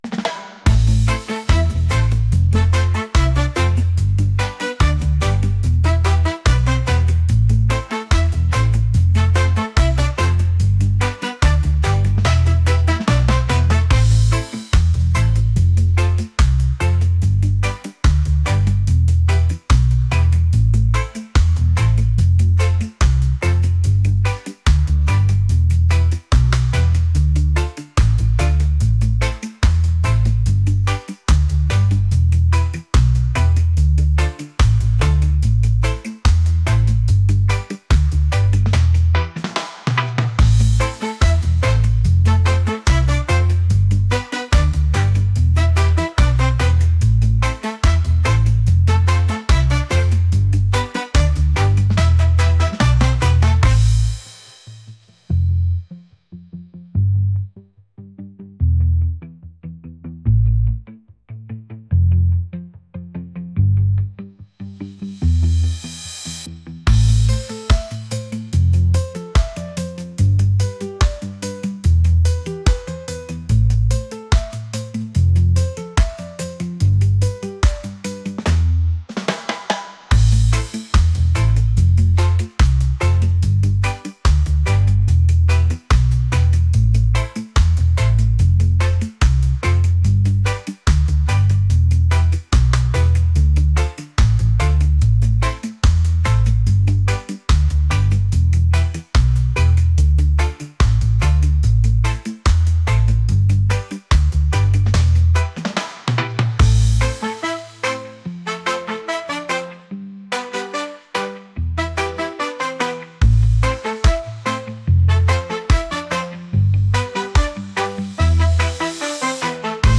upbeat | reggae | catchy